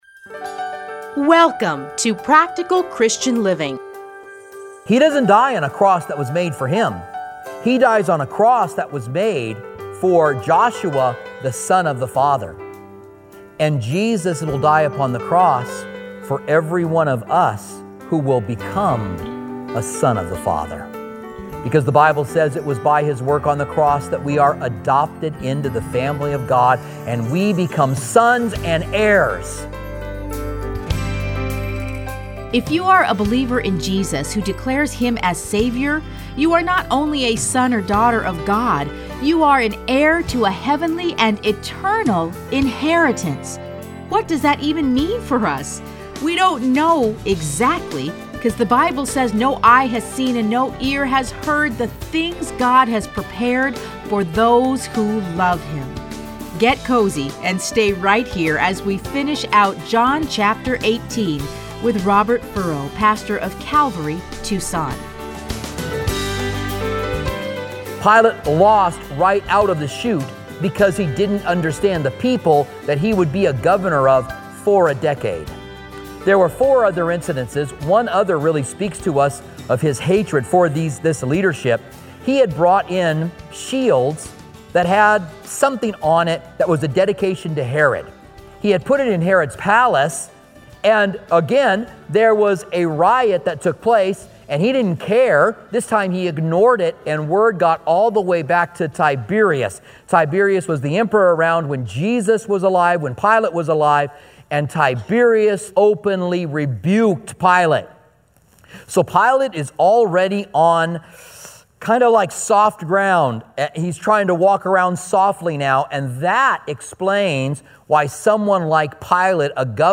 Listen to a teaching from John 18:28-40.